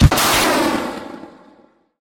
rocket-launcher-2.ogg